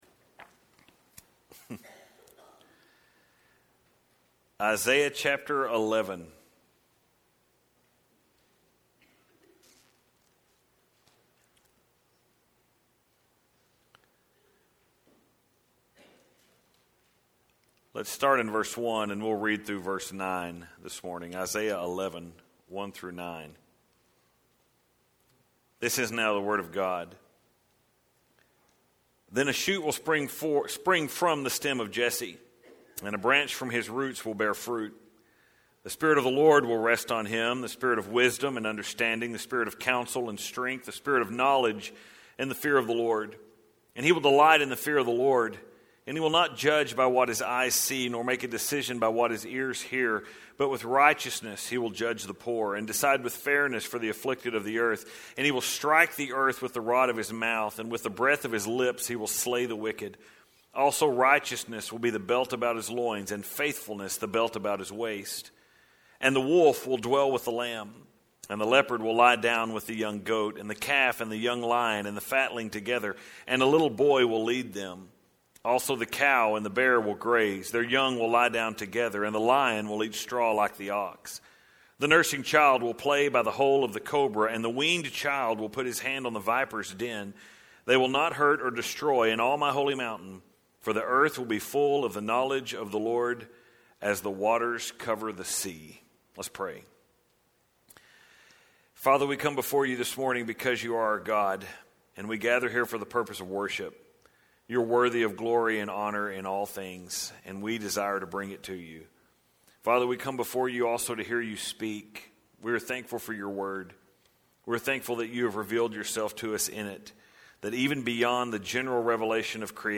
download here The Saving King – part 3 Isaiah 11:1-16 (6-9) June 4, 2023 On Thursday while working on this sermon I decided to do an internet search of the headlines for that day.